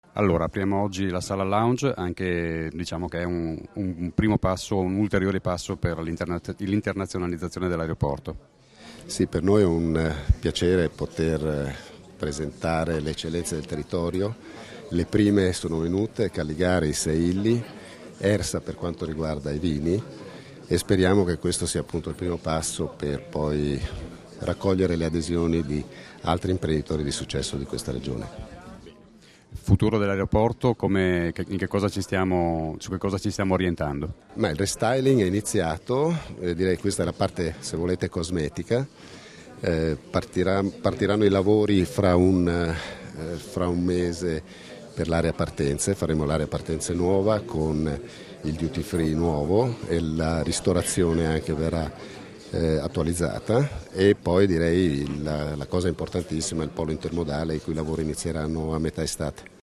a margine dell'inaugurazione della Sala Calligaris VIP Lounge all'Aeroporto FVG, rilasciate a Ronchi dei Legionari il 6 aprile 2016